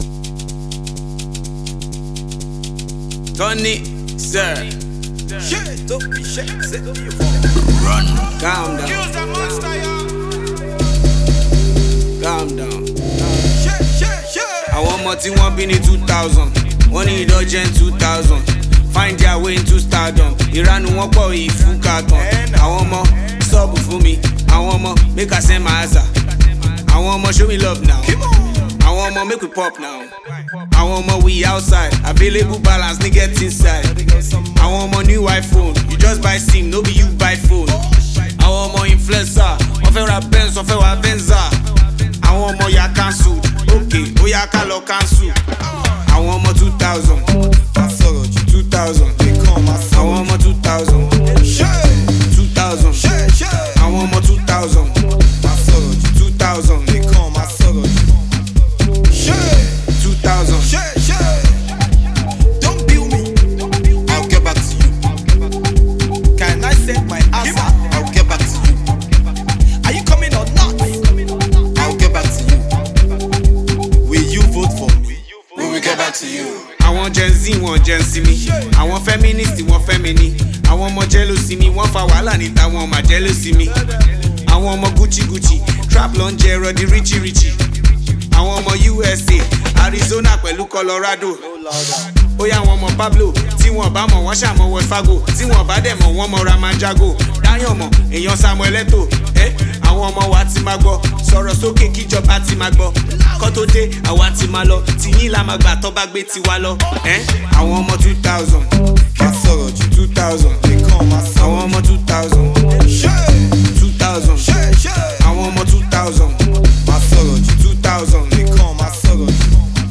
Award winning Nigerian singer and Afrorapper